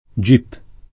GIP [ J ip ] o gip [ id. ] s. m. — sigla di Giudice per le Indagini Preliminari